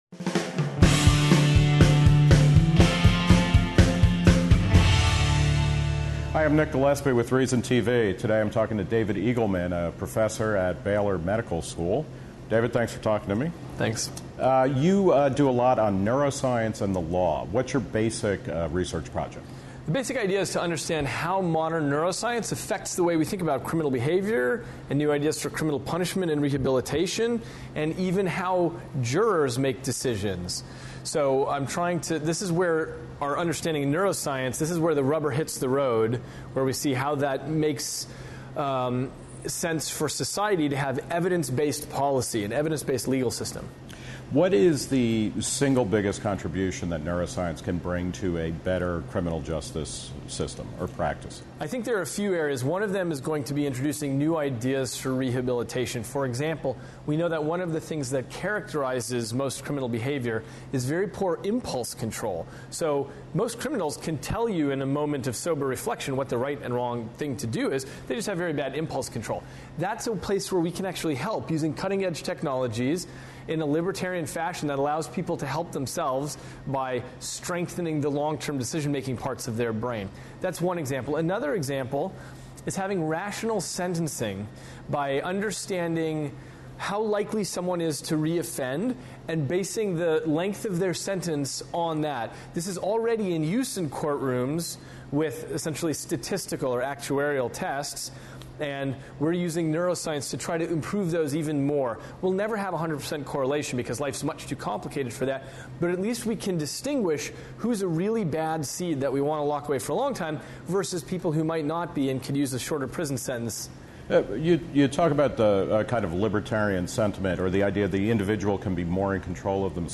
Interview by Nick Gillespie Scroll down for downloadable versions.